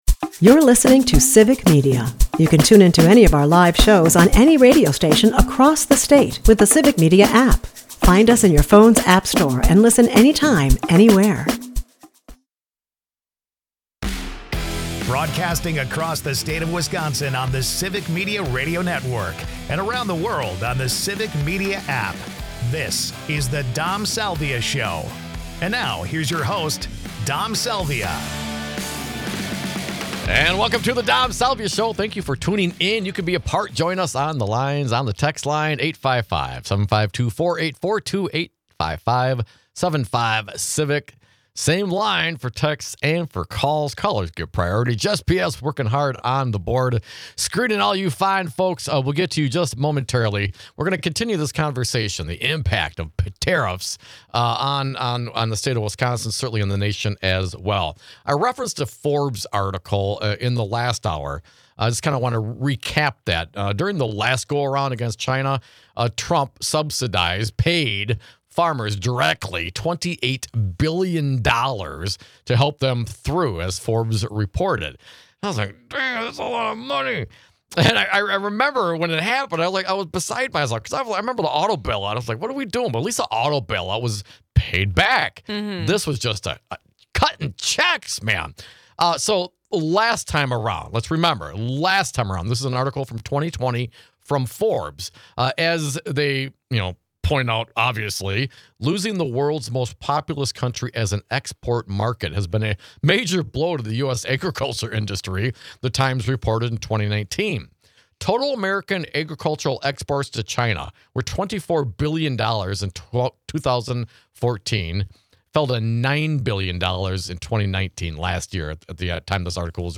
We talk with our audience about the devastating impact Trump's tariffs will have on Wisconsin.